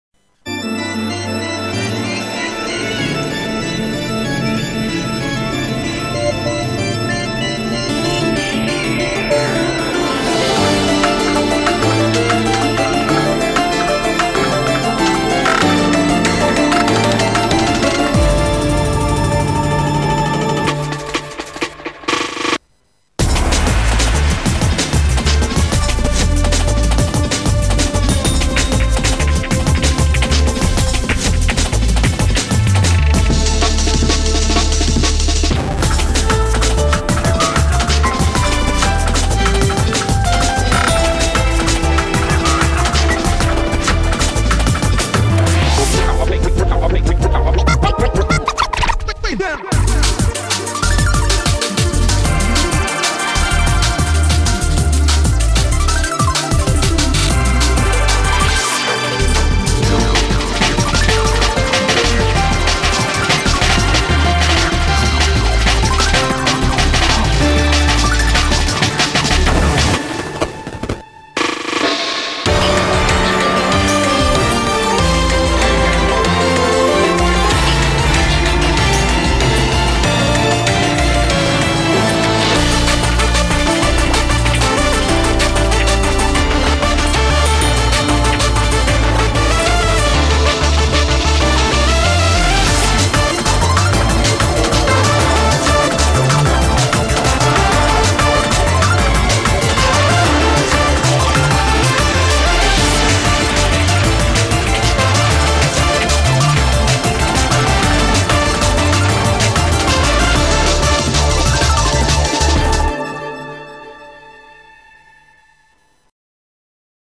BPM95-190
Audio QualityPerfect (High Quality)
Genre: Breakcore